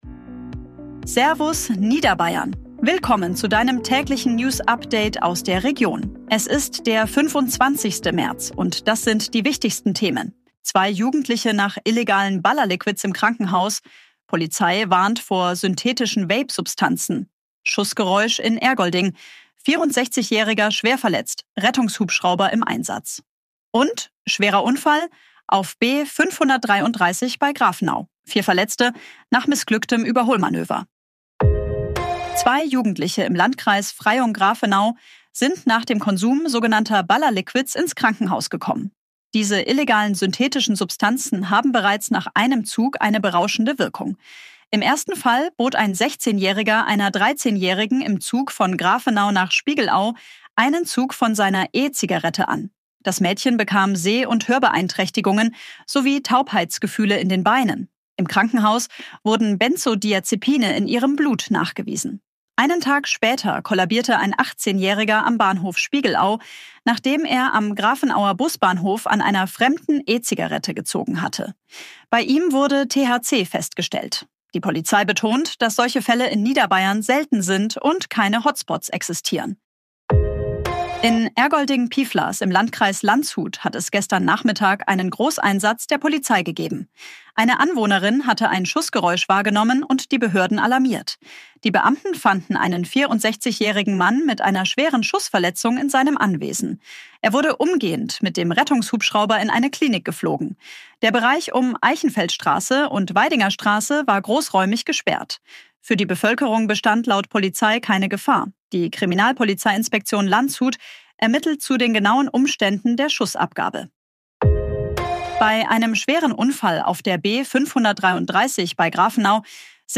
Tägliche Nachrichten aus deiner Region
Dein tägliches News-Update